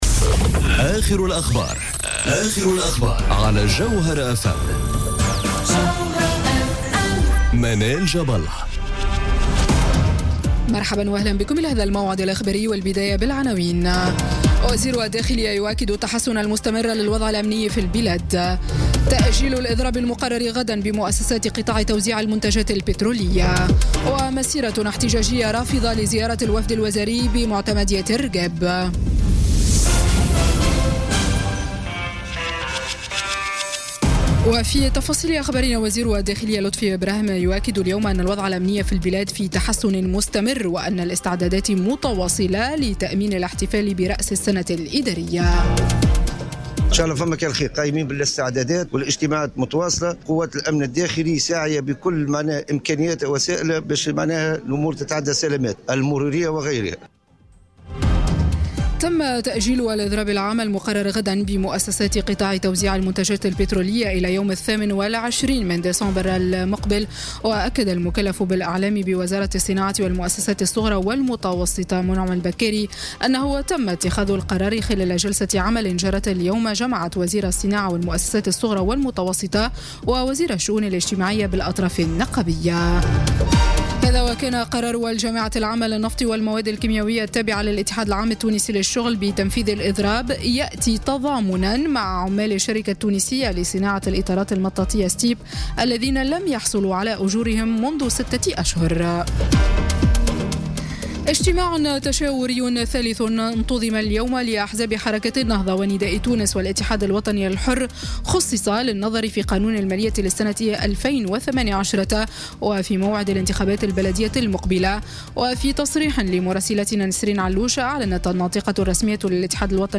نشرة أخبار السابعة مساءً ليوم الخميس 14 ديسمبر 2017